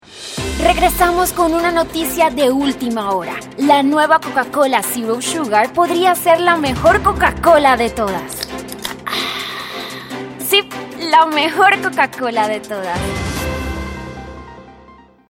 Anuncios
Voz versátil y expresiva, hablante nativa de español.
Tono neutro latinoamericano y acentos colombianos.
Tono: Medio (Natural) y Alto-Bajo Opcional.
Acentos: Español colombiano nativo y español neutro LATAM.